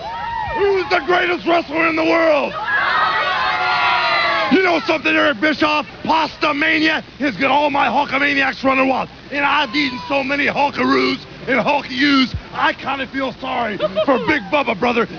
interviews like this one.